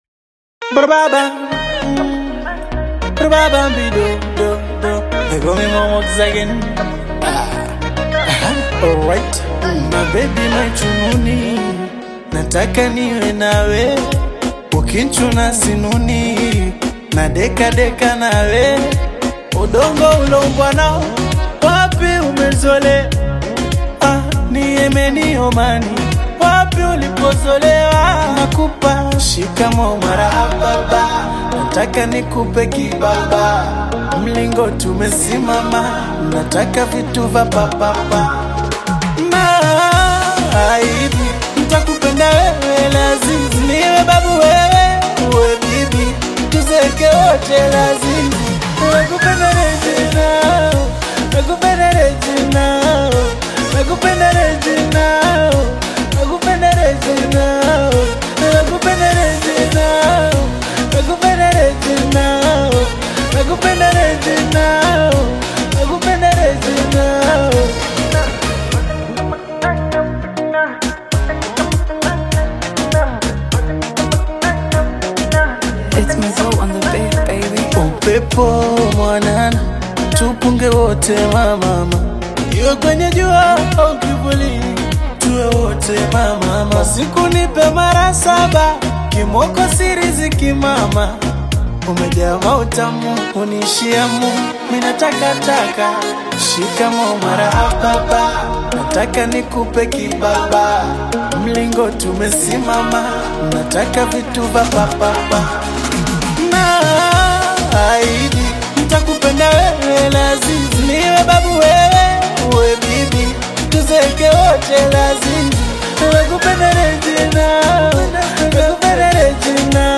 atmospheric soundscapes